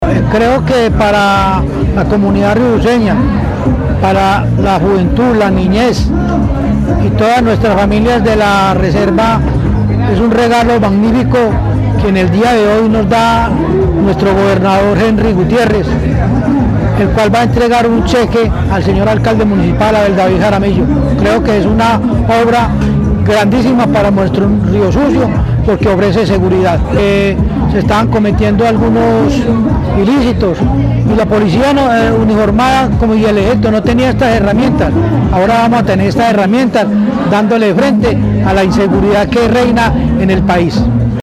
habitante de Riosucio.